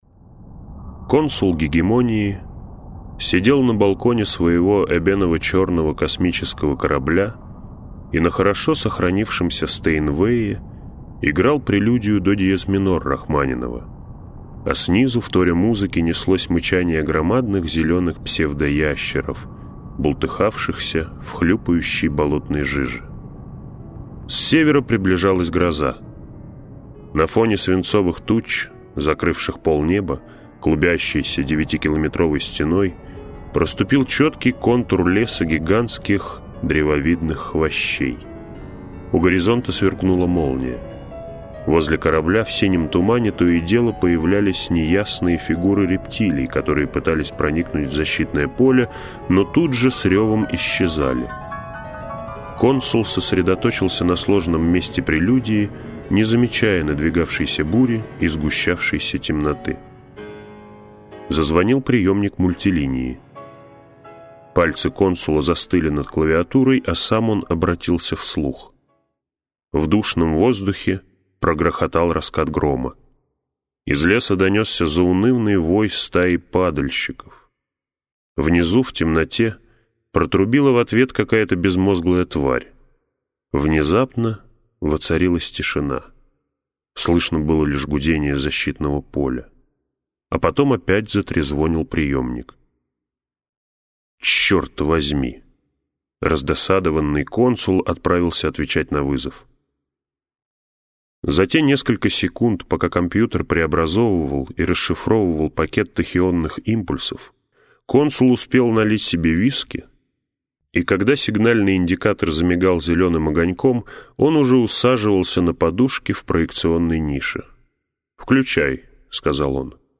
Аудиокнига Гиперион - купить, скачать и слушать онлайн | КнигоПоиск